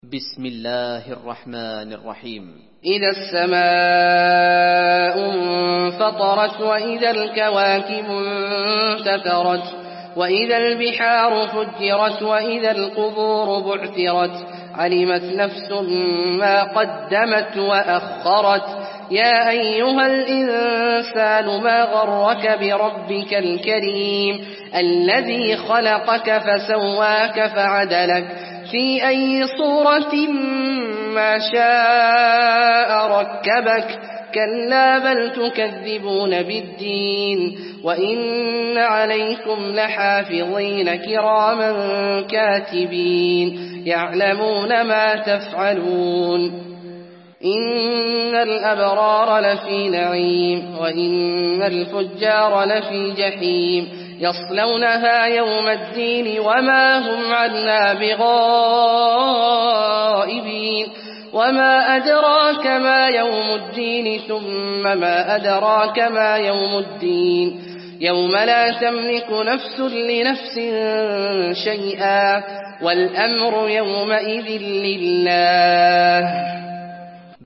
المكان: المسجد النبوي الانفطار